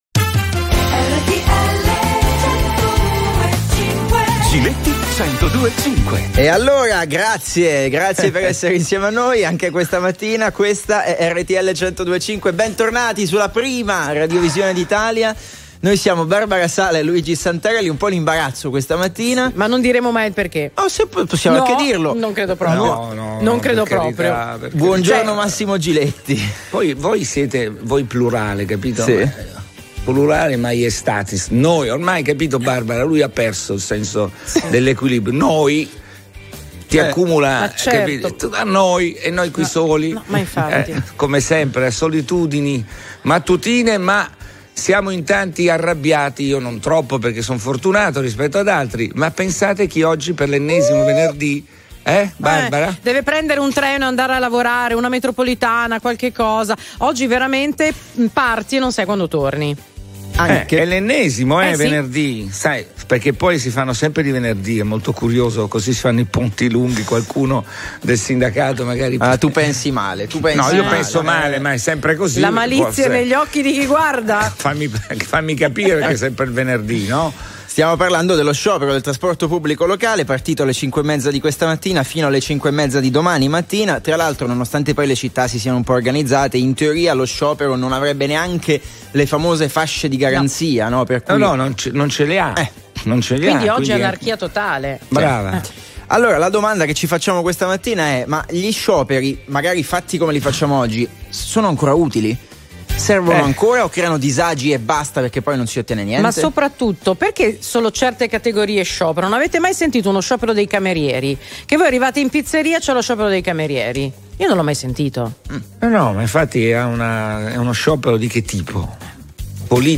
Ospite dei trecento secondi, in Giletti 102.5, Bruno Vespa.
Telefoni aperti ai Very Normal People sui fatti della settimana. La domanda di oggi è se gli scioperi sono ancora utili.